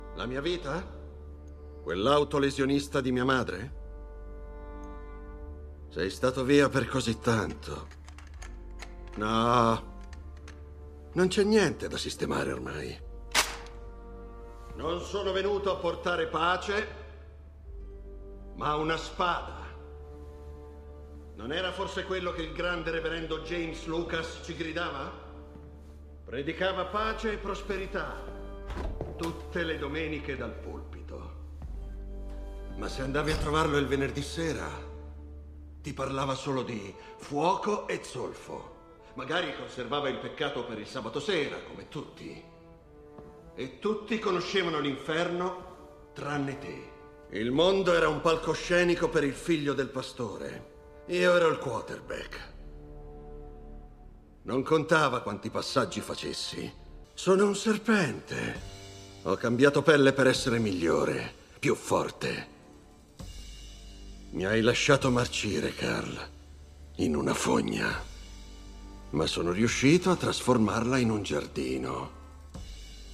Il mondo dei doppiatori